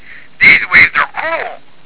Said psychoticly